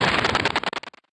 tesla-turret-beam-deflection-1.ogg